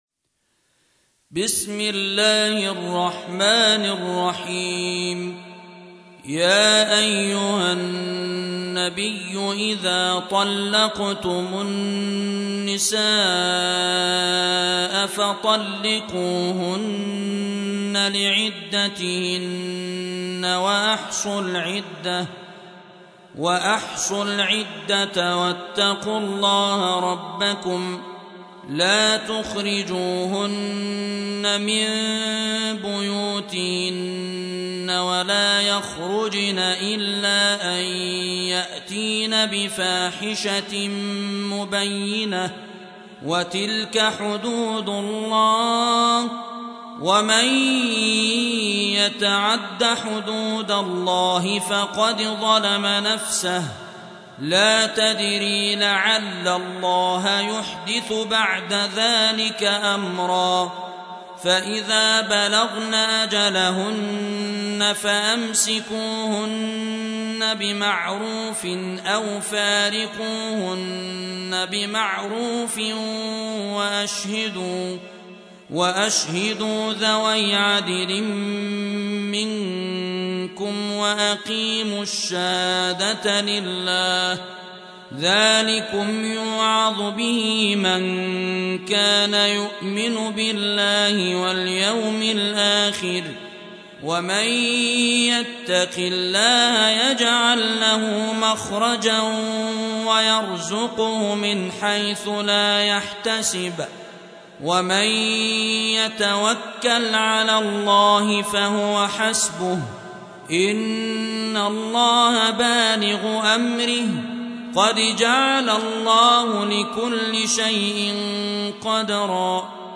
65. سورة الطلاق / القارئ